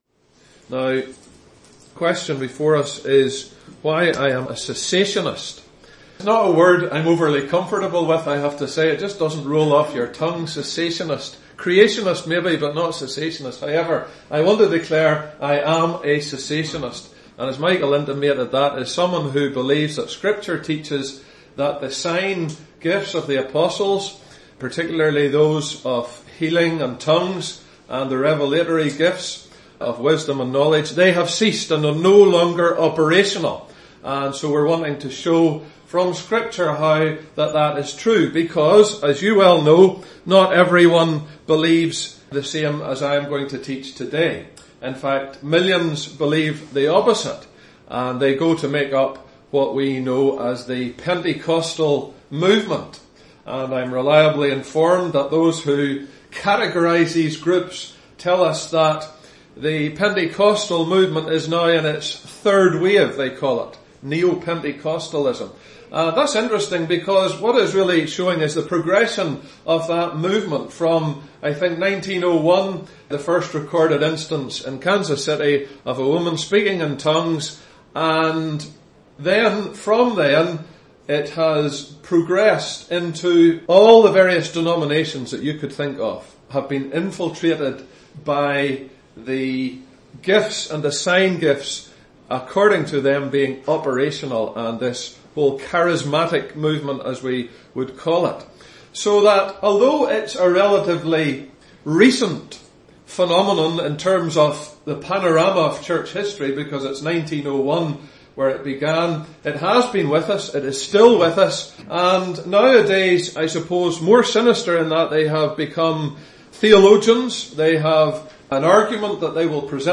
His reasons are: 1. Scripture says these gifts have ceased; 2. They have served their purpose; 3. Tthe apostles have passed away; 4. The Bible has been completed; 5. Modern tongues and healing fail the test of discerning Biblical scrutiny (Readings: 1 Cor 13:9-13, 14:22-26, 2 Cor 12:12, 1 PEt 4:7-11) (Message preached 24th Mar 2019)